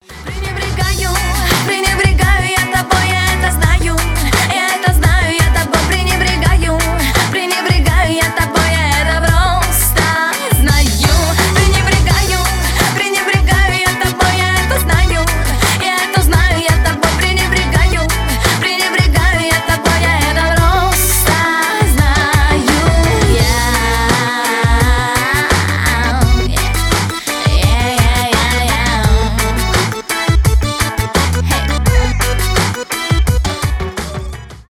поп
аккордеон
rnb